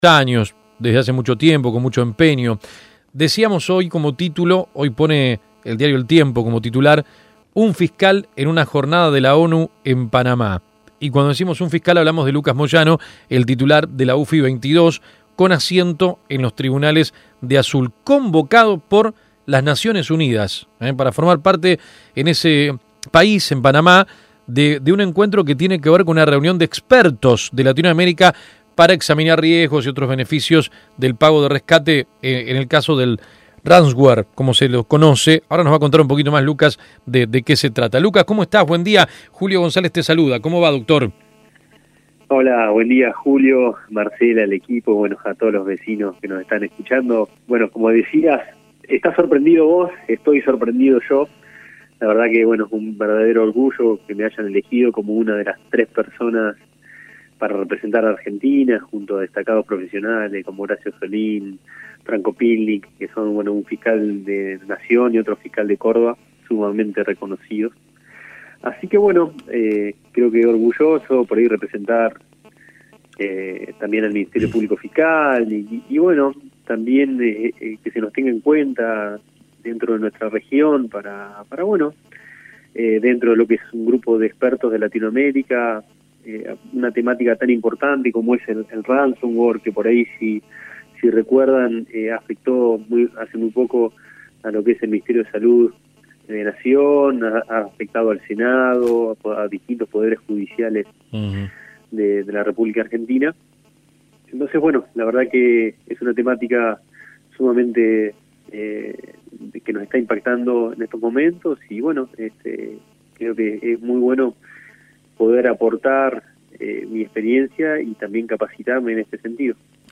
El Fiscal Lucas Moyano paso por la mañana de AM 1210 a continuación compartimos la nota: